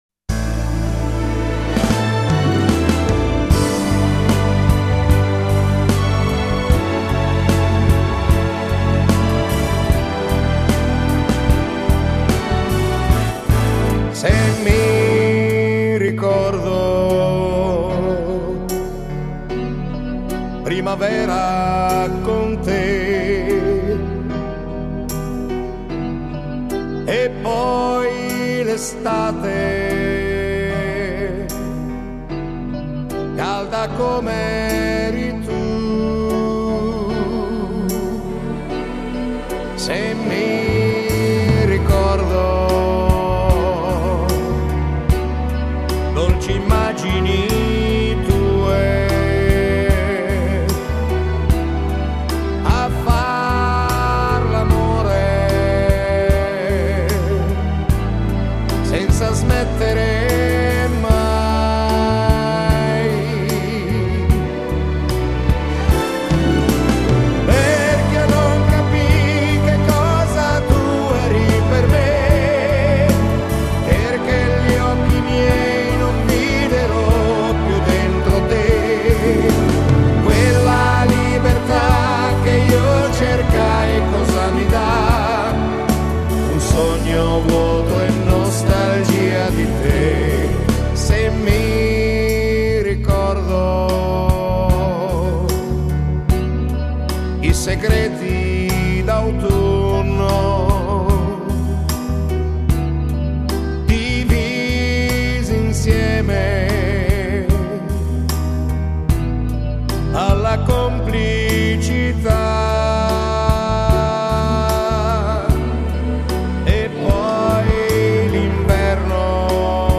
Genere: Lento